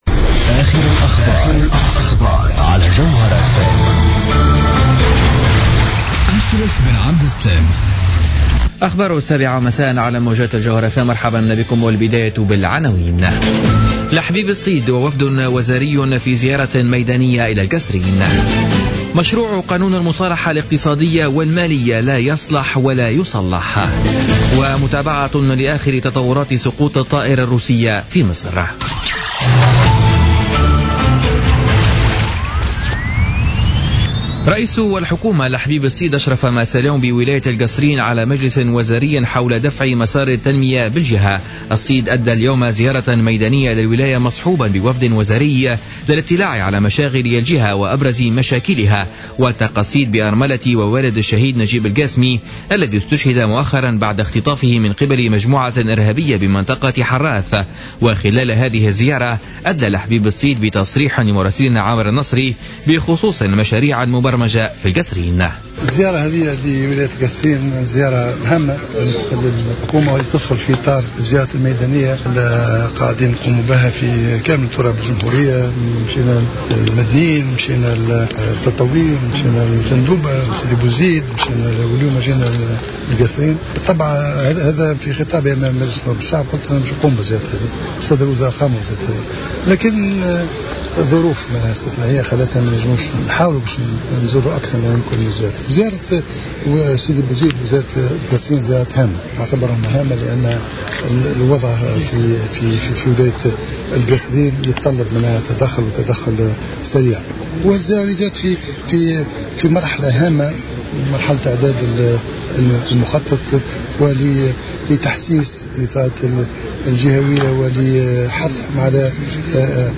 نشرة أخبار السابعة مساء ليوم السبت 31 أكتوبر 2015